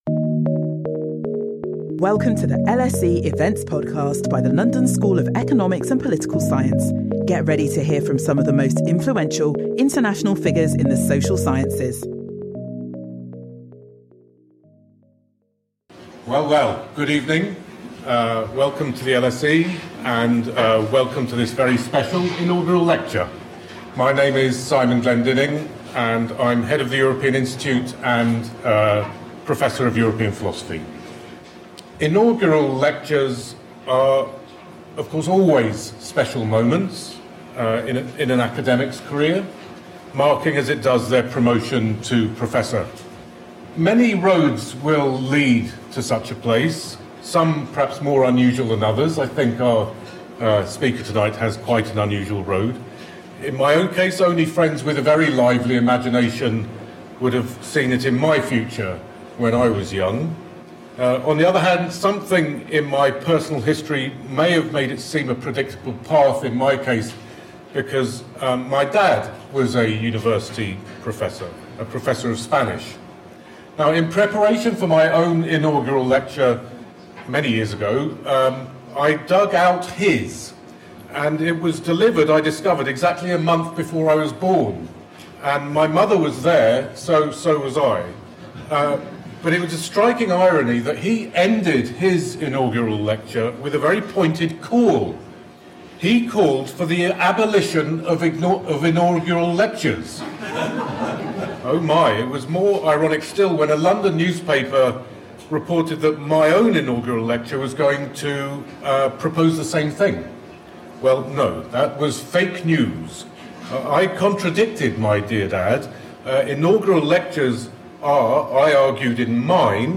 In her inaugural lecture